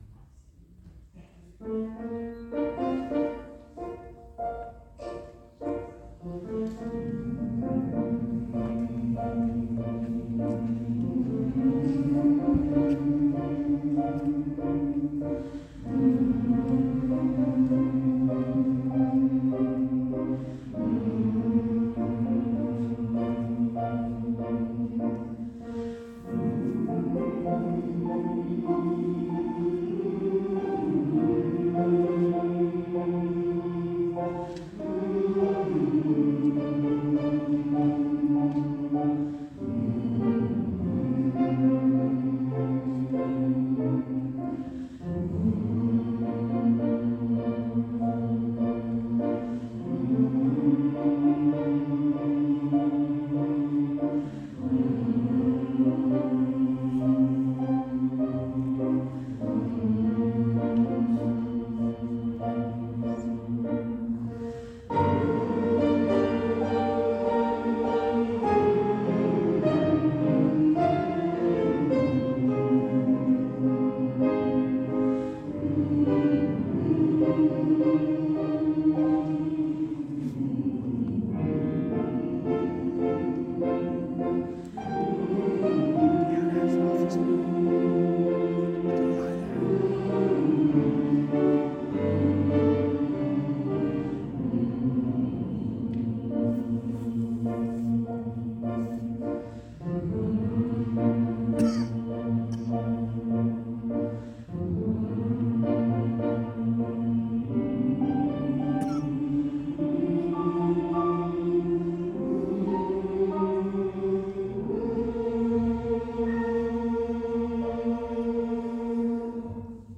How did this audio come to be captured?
The following audio recordings are snippets from previous concerts to give you a taste of our repertoire